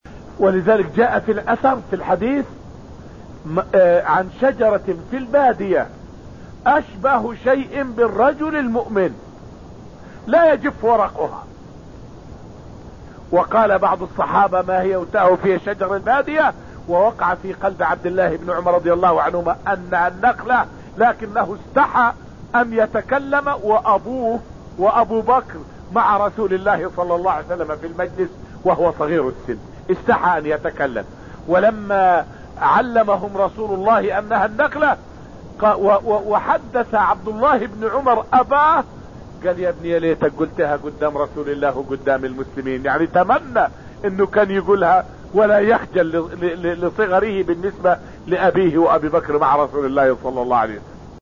فائدة من الدرس الخامس من دروس تفسير سورة الرحمن والتي ألقيت في المسجد النبوي الشريف حول أدب وحياء الصحابة رضي الله عنهم.